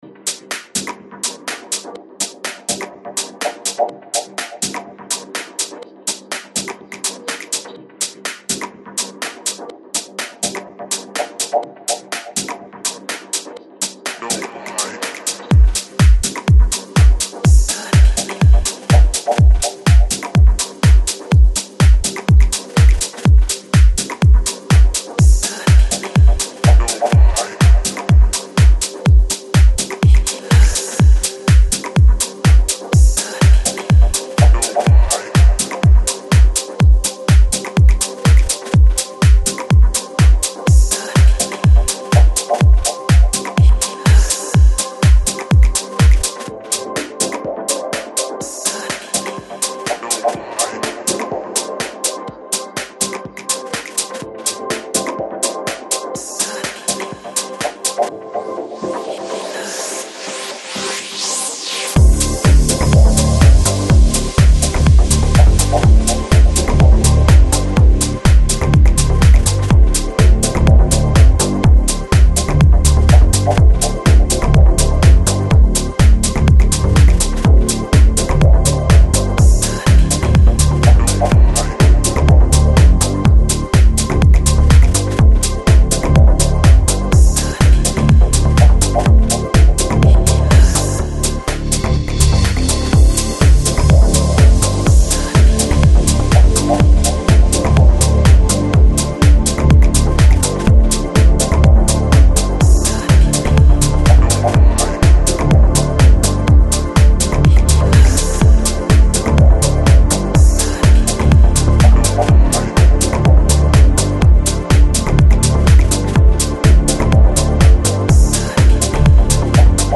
Electronic, Deep House, Soulful House